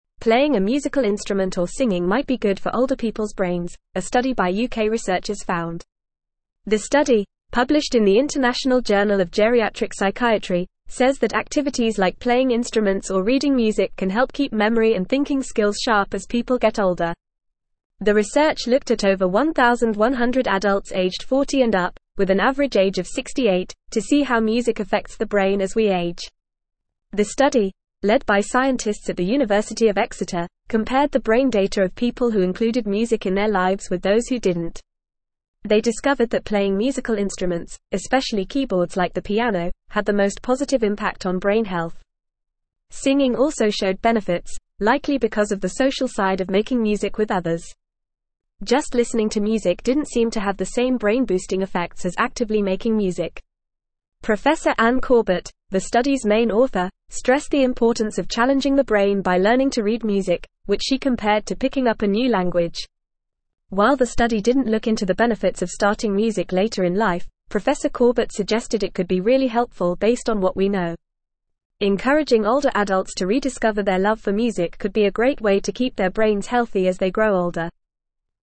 Fast
English-Newsroom-Upper-Intermediate-FAST-Reading-Music-and-Brain-Health-Benefits-of-Playing-Instruments.mp3